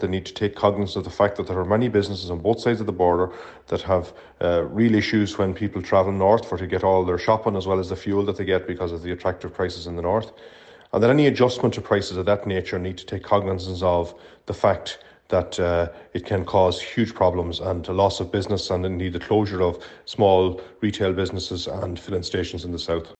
Sinn Fein’s Transport spokesperson is South Donegal Deputy Martin Kenny. He says an increase in petrol and diesel prices here will be damaging for smaller businesses………